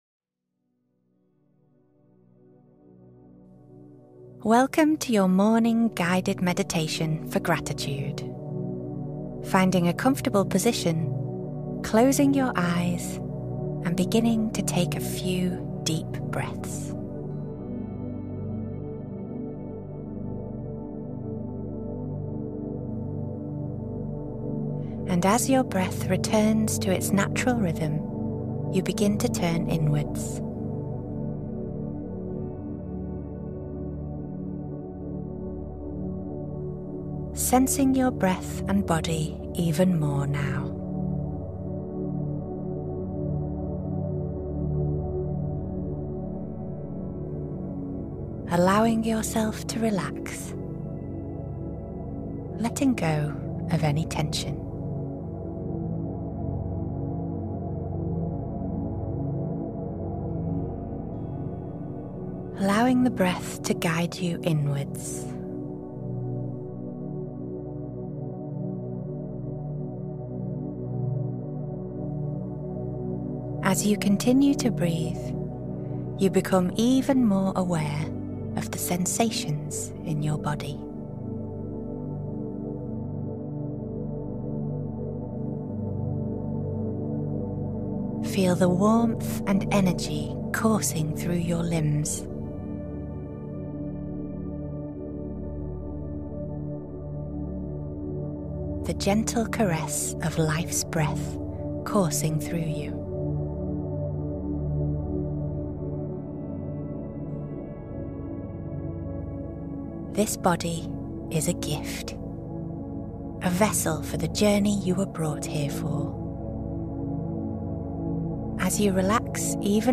That’s why all advertisements are placed at the beginning of each episode—so once you press play on Zen Meditation, nothing interrupts the space you’re creating for yourself, and so you can also support the continued growth of Zen Meditation.